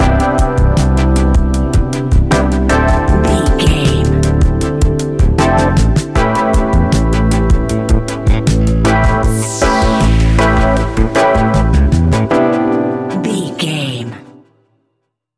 Ionian/Major
A♭
laid back
Lounge
sparse
new age
chilled electronica
ambient
atmospheric